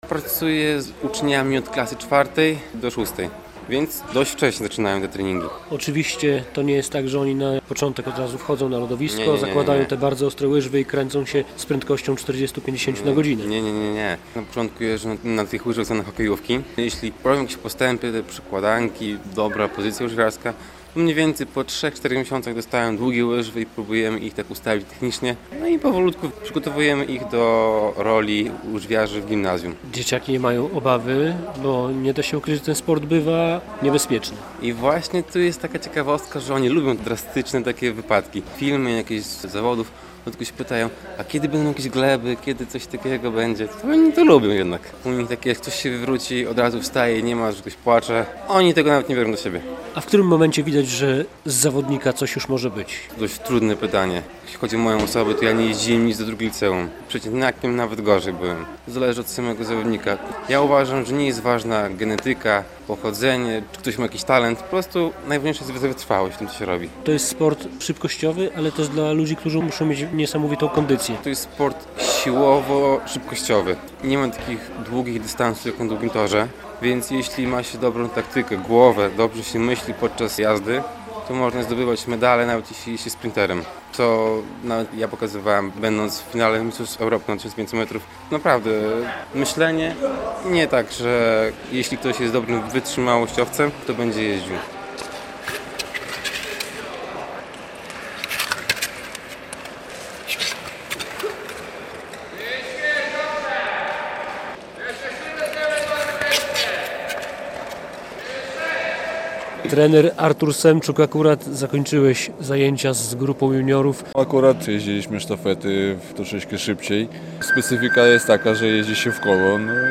Młodzi adepci short-tracku w Juvenii Białystok - relacja